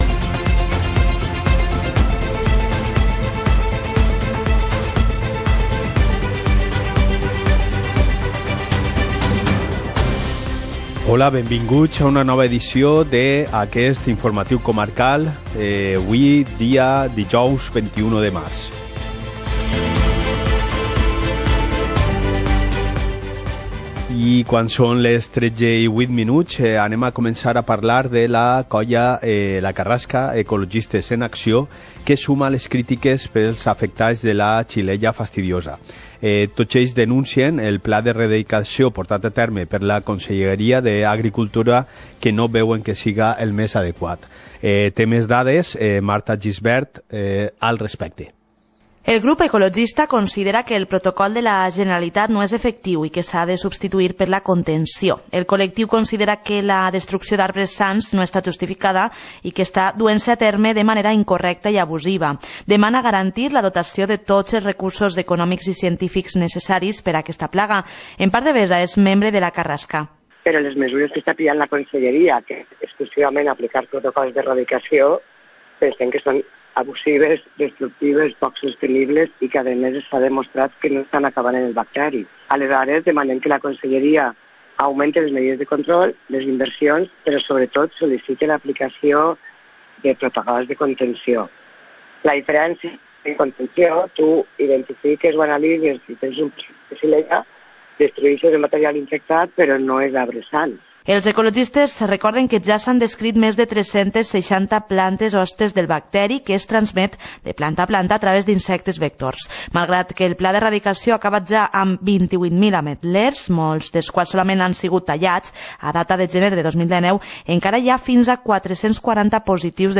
Informativo comarcal - jueves, 21 de marzo de 2019